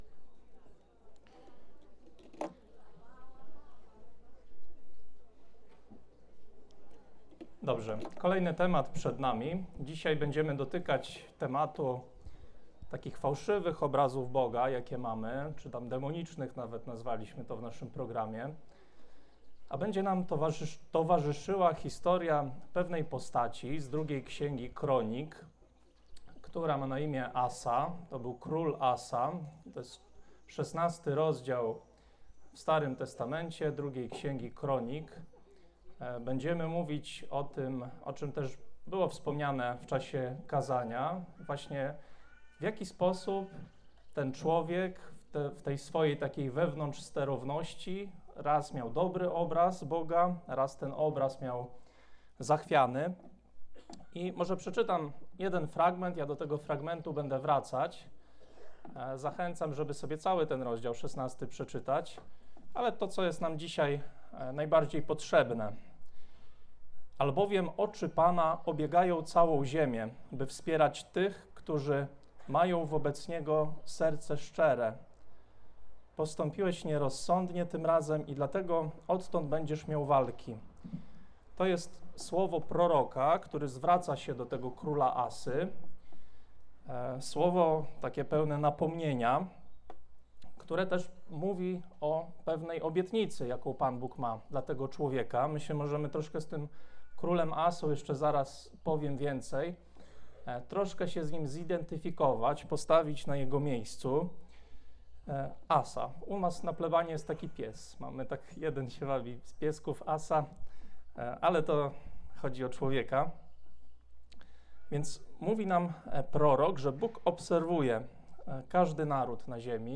Temat dnia: Demoniczne obrazy Boga - konferencja + modlitwa (słuchaj konferencji) Środa 28.05.2025r. Temat dnia: Ojcze Nasz - zasady królestwa Bożego